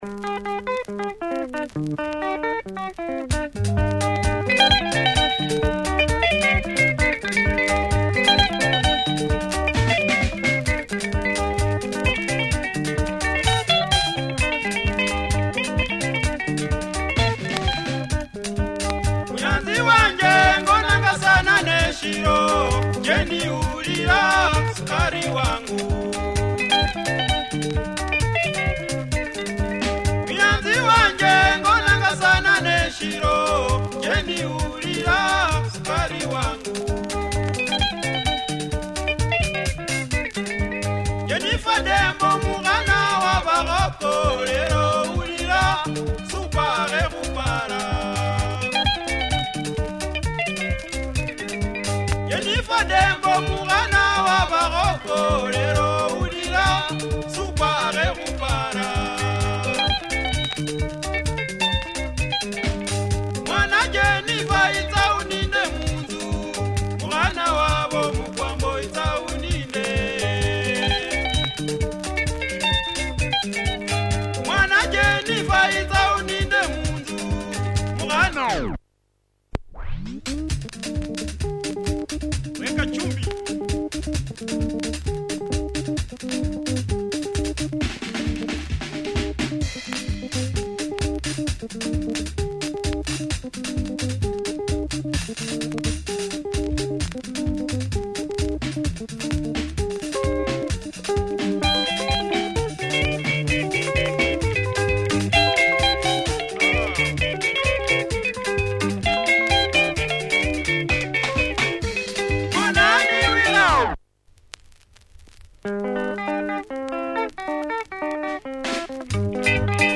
Nice luhya benga, clean as clean can be.